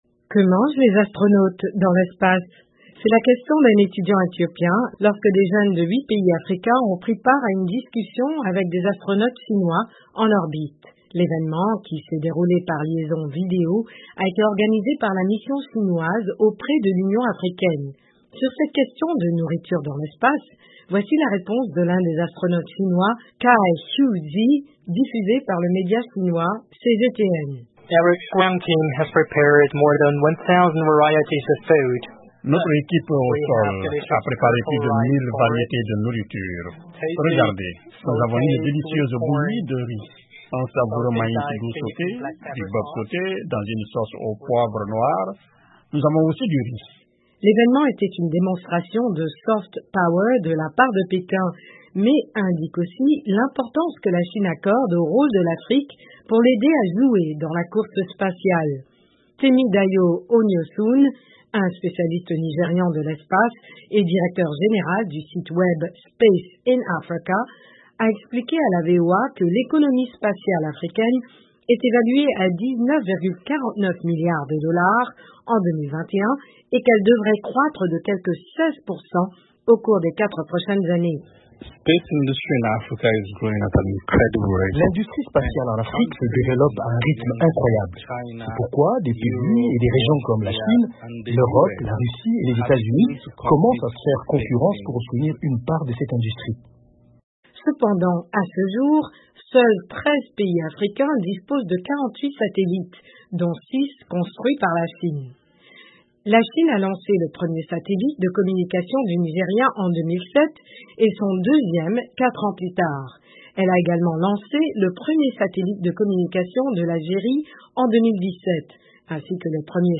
La nouvelle frontière de la Chine dans sa quête d'influence en Afrique va au-delà de la planète Terre. Le géant asiatique considère le continent comme un partenaire dans ses ambitions spatiales. Le reportage